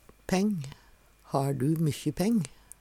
peng - Numedalsmål (en-US)